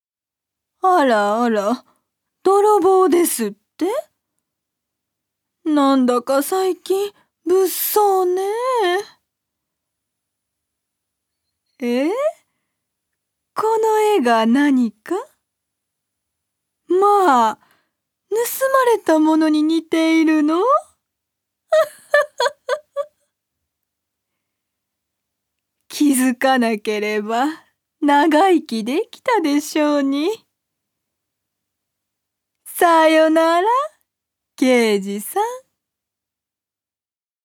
女性タレント
セリフ２